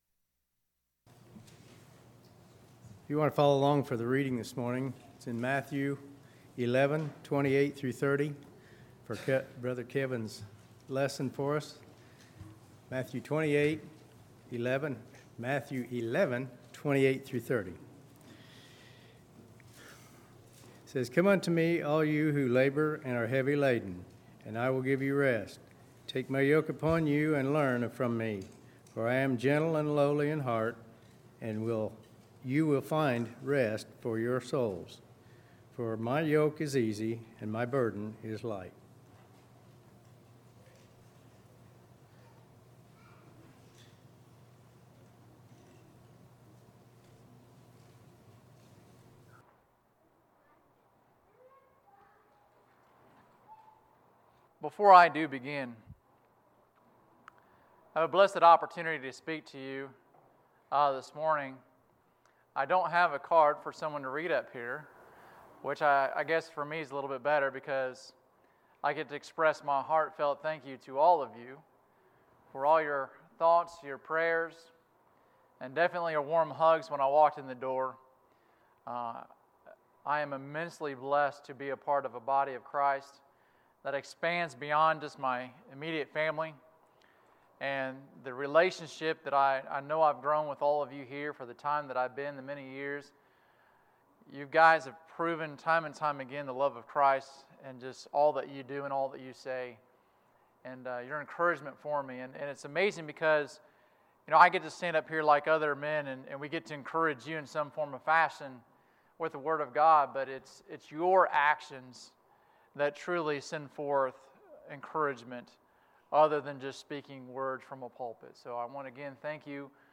Sermons, August 18, 2019